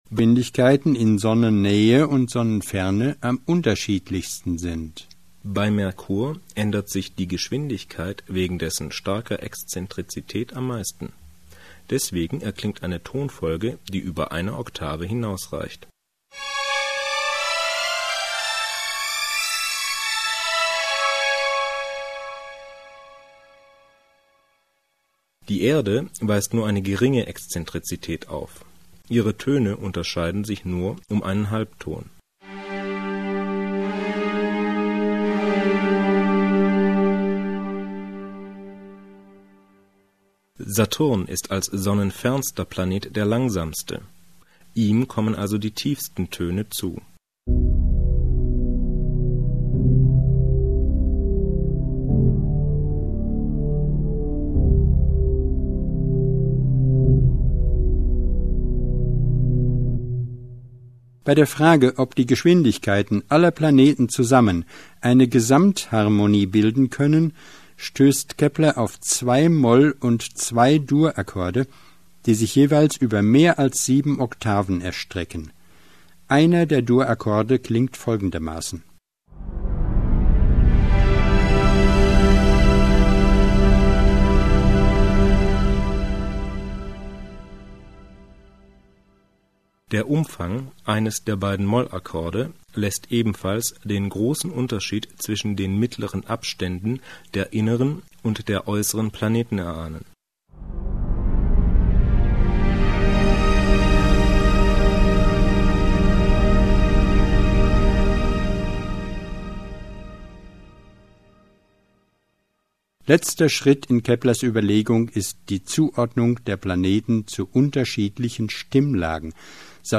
• 土星唱「低沉的大三度」。
• 木星唱「庄严的小三度」。
• 火星唱「激昂的纯五度」（变化最大）。
• 地球唱「微弱的半音」（近乎圆形轨道）。
• 金星唱「几乎单调的音」（最接近圆形）。
• 水星唱「跳跃的八度」（偏心率最大）。
以下是演奏出来各个行星的音乐：